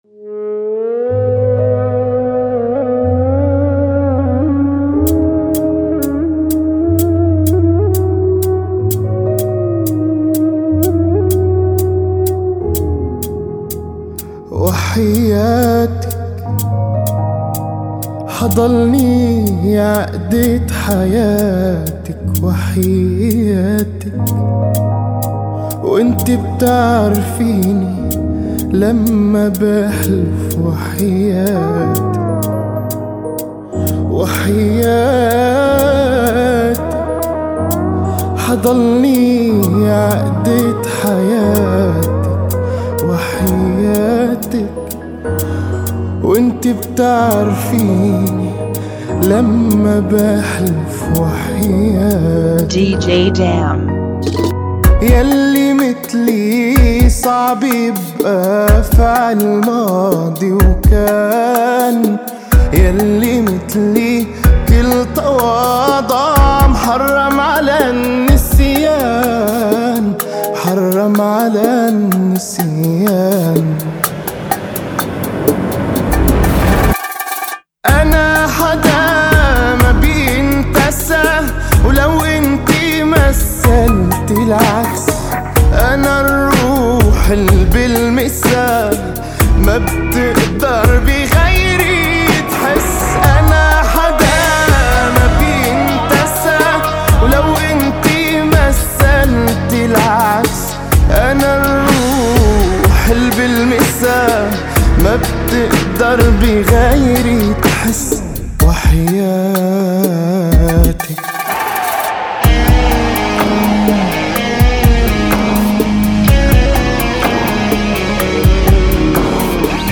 124 BPM
Genre: Bachata Remix